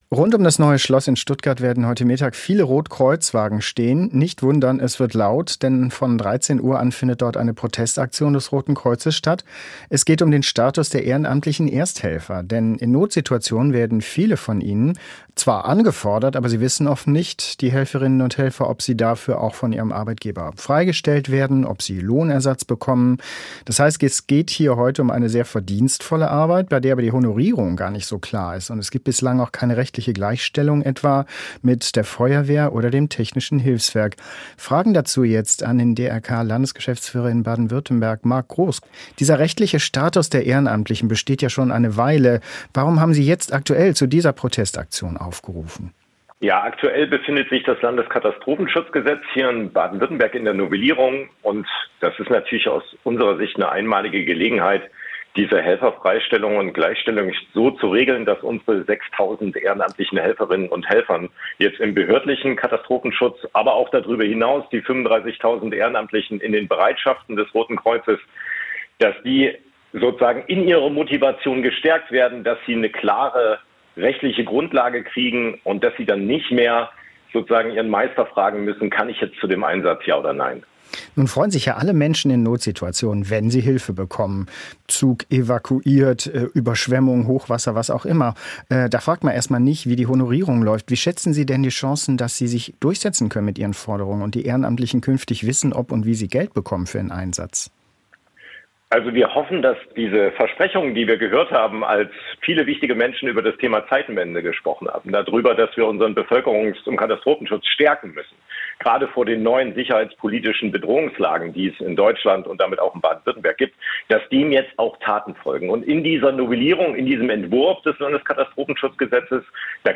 Das Interview haben wir vorher geführt.